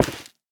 Minecraft Version Minecraft Version snapshot Latest Release | Latest Snapshot snapshot / assets / minecraft / sounds / block / stem / break2.ogg Compare With Compare With Latest Release | Latest Snapshot
break2.ogg